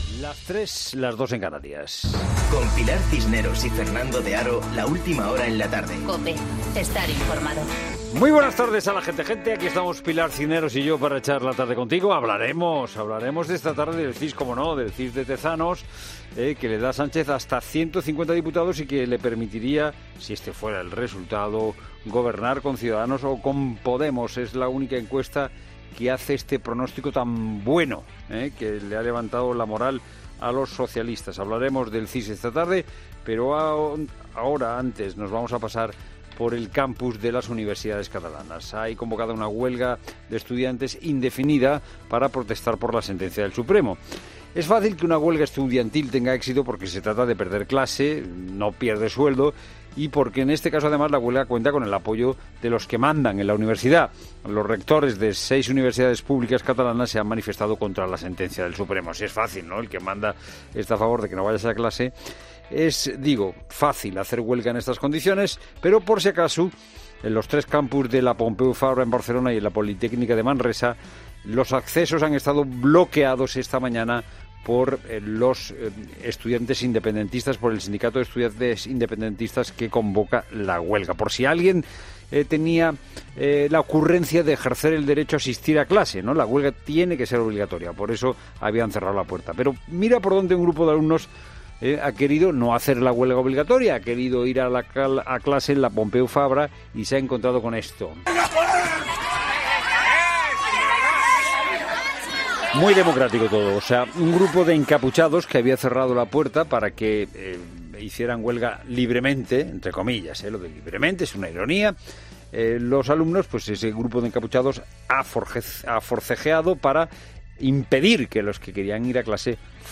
Monólogo de Fernando de Haro
El presentador de 'La Tarde' analiza toda la actualidad en su monólogo de las 15:00 horas